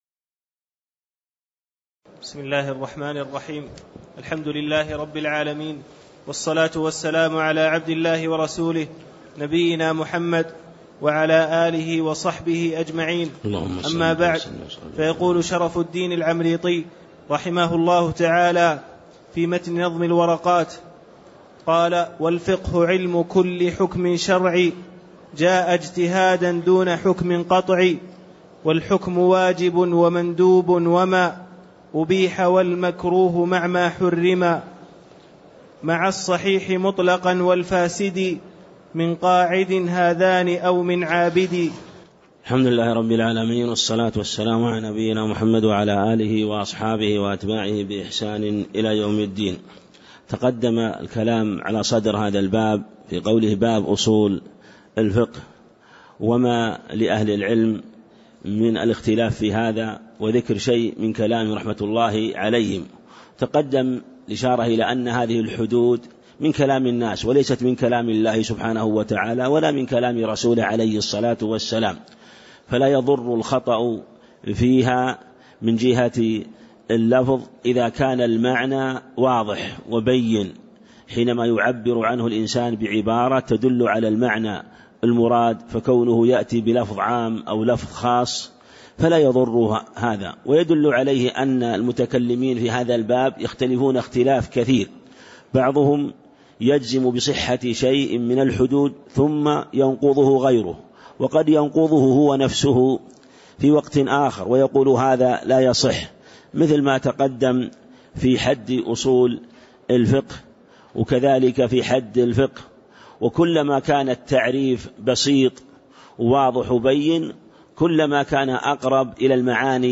تاريخ النشر ١٢ شوال ١٤٣٦ هـ المكان: المسجد النبوي الشيخ